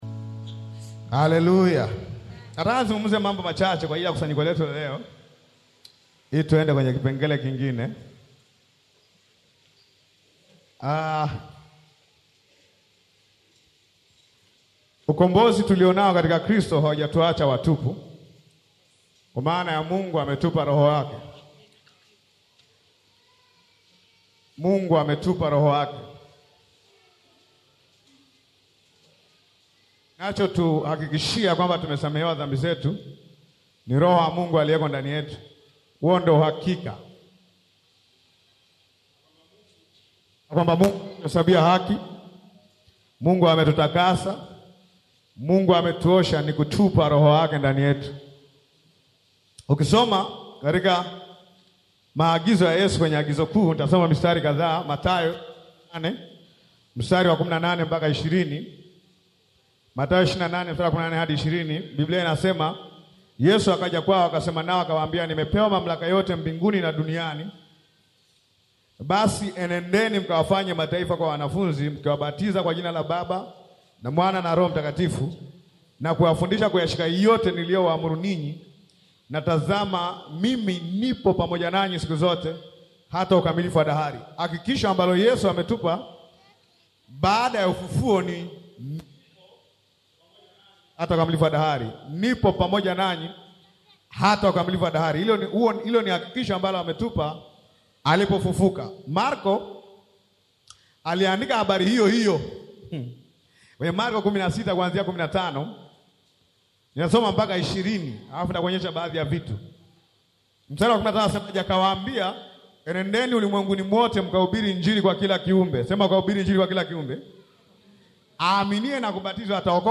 KUSANYIKO-LA-ROHO-MTAKATIFU-HOLY-GHOST-MEETING.mp3